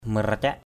/mə-ra-caʔ/ (mara < cak) 1.